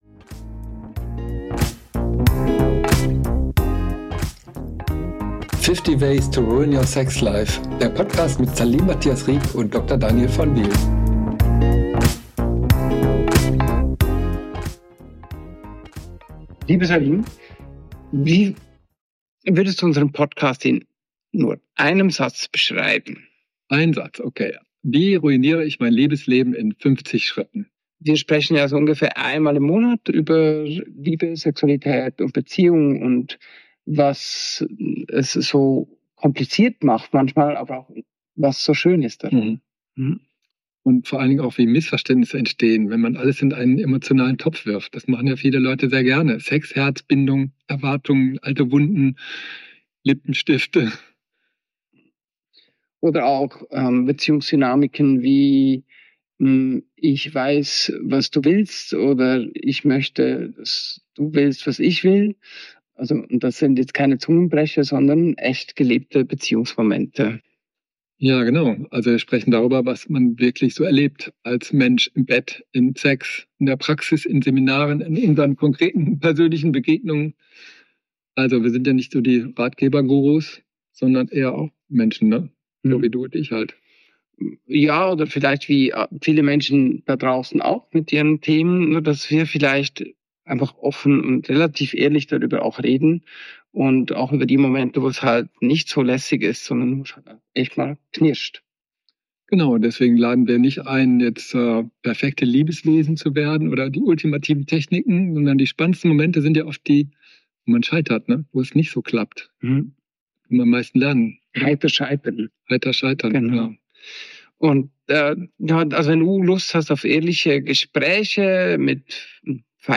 Sondern ehrliche Gespräche über das, worüber sonst meist